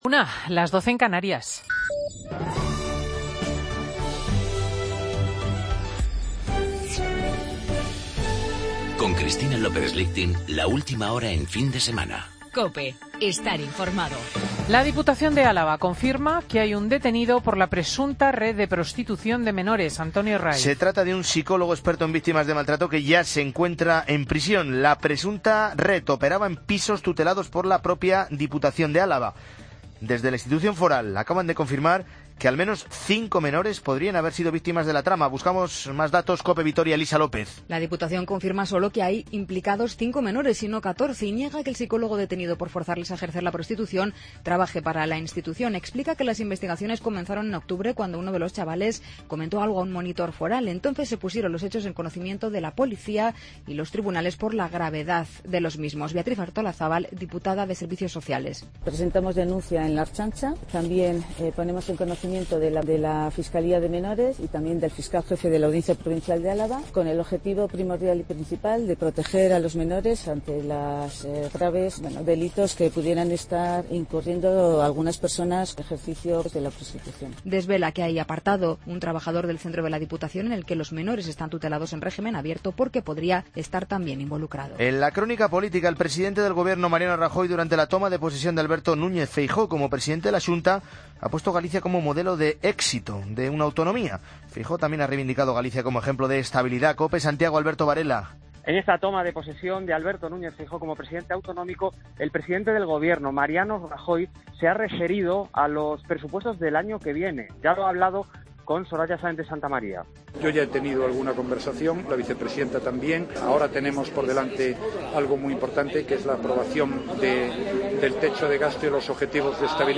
Boletín de noticias de las 13 horas